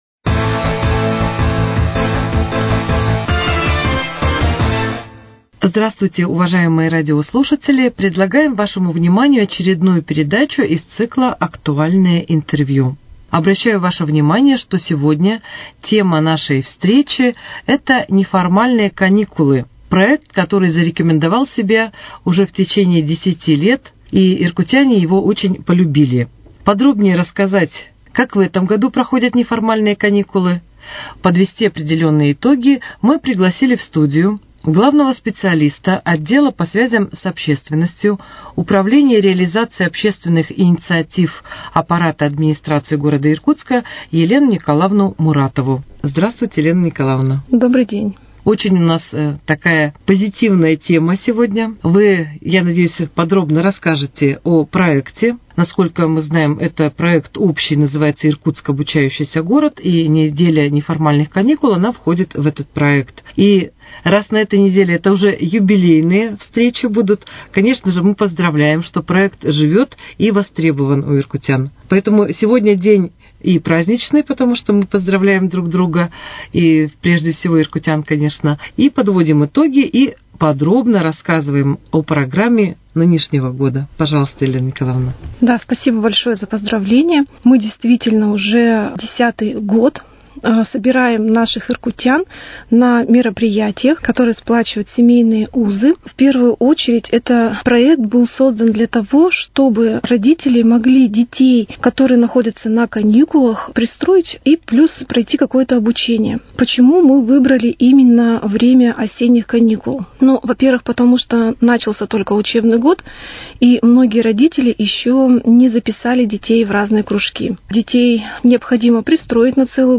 Актуальное интервью: «Неформальные каникулы» в Иркутске